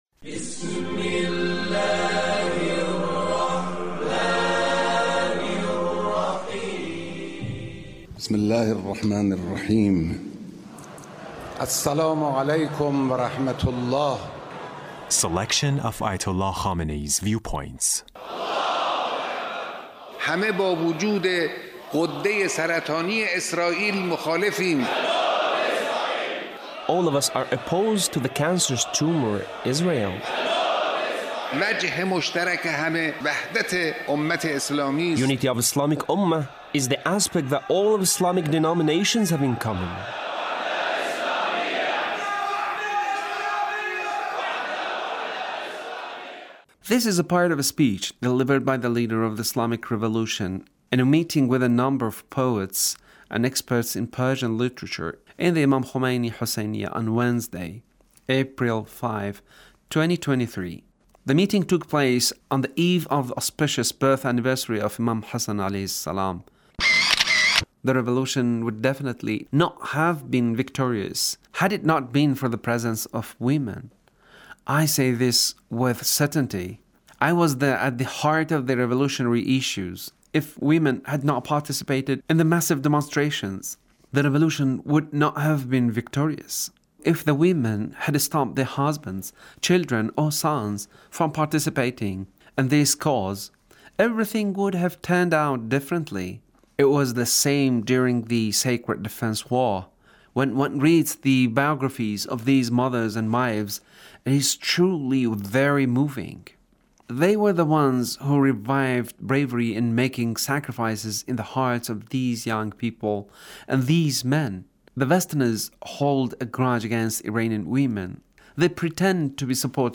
Leader's Speech on Poet's Gathering